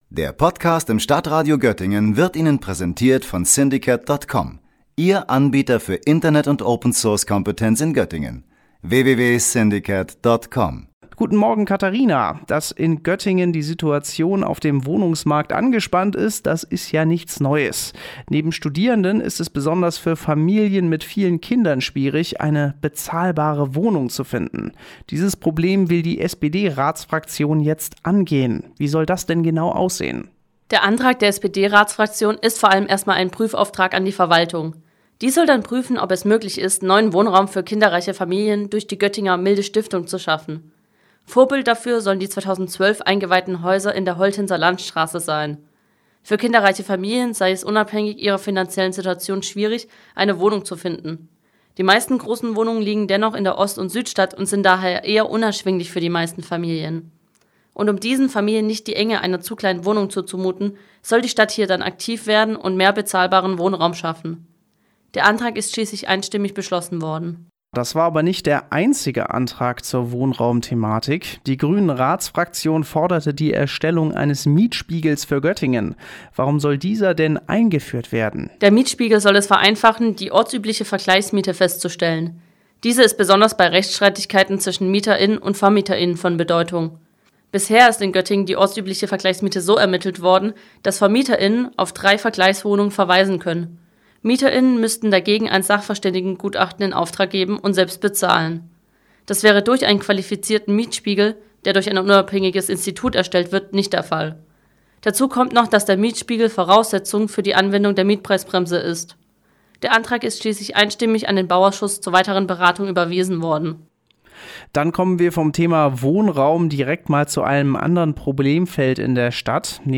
StadtRadio-Reporterin